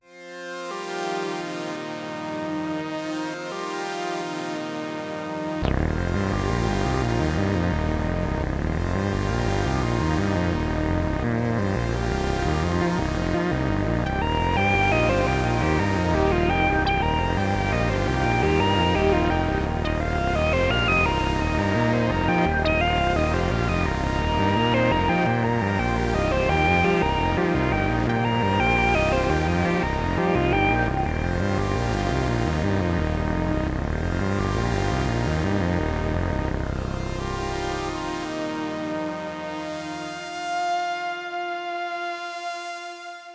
Solving puzzles and bringing this world back to life also unlocks elements for use in a fully-featured electronic music “Studio.”
video-games-and-the-bible-fract-mix.wav